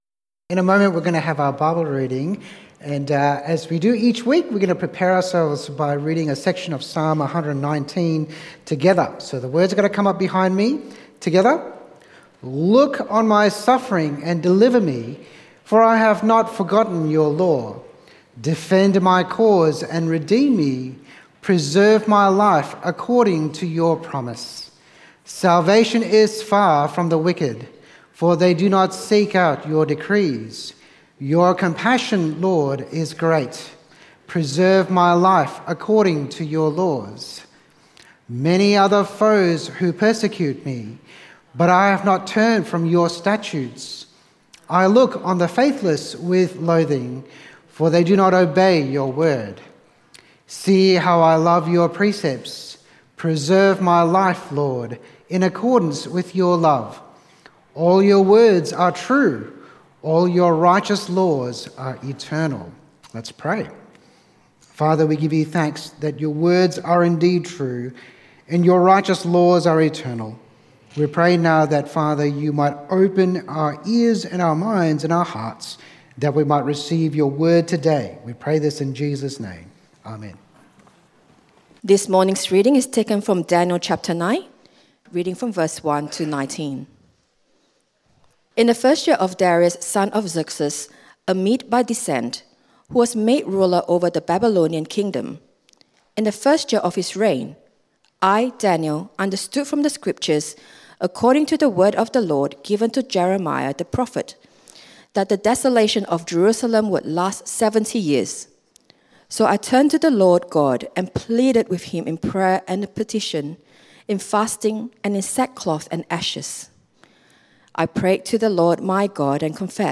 Great Prayers in the Bible Sermon outline